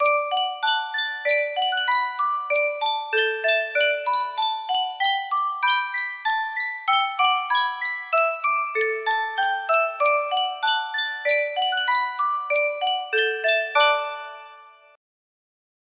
Sankyo Music Box - Anchors Aweigh LP music box melody
Full range 60